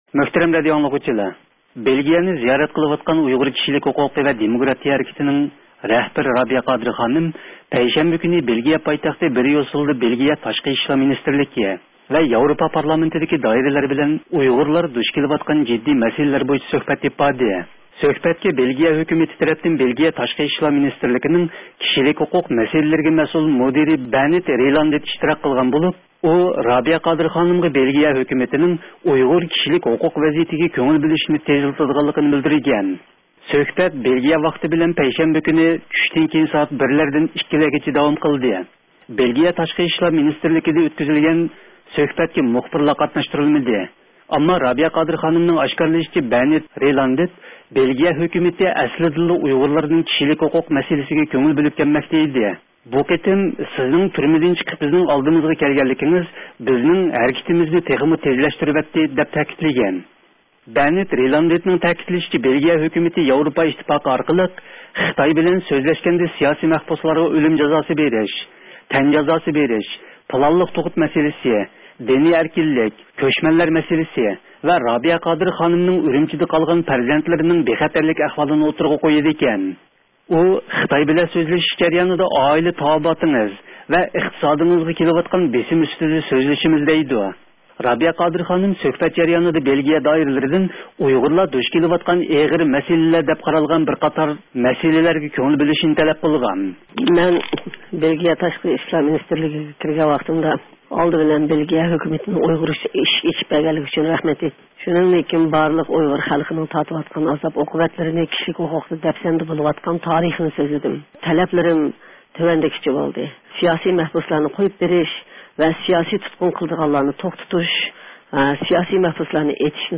بريۇسسېلدىن بەرگەن خەۋىرىدىن